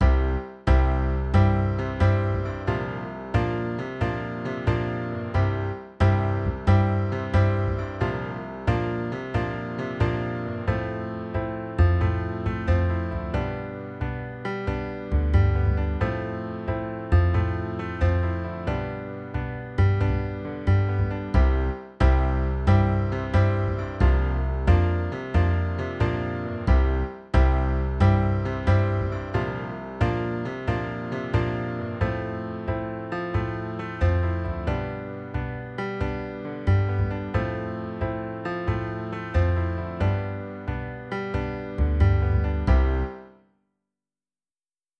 Easy Pop Rock Example With Swing 8ths
In this example, you can practice a swing 8th note rhythm.
Ex-2-Easy-Pop-Rock.mp3